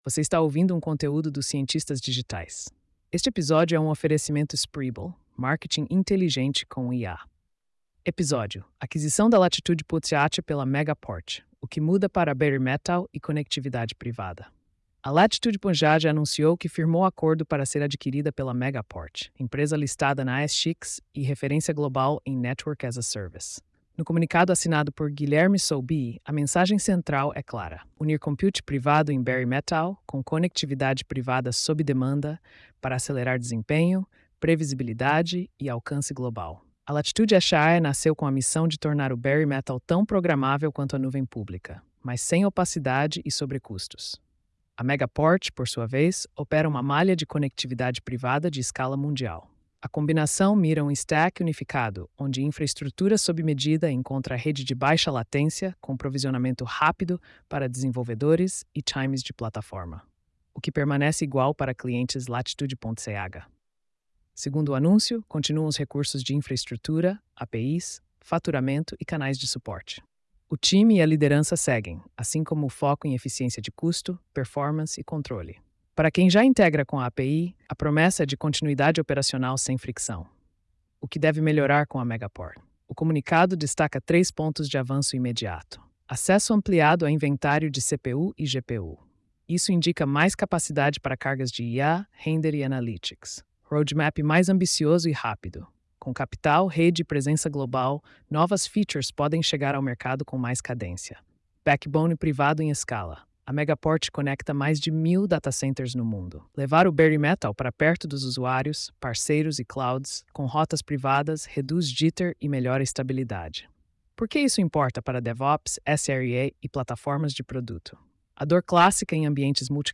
post-4532-tts.mp3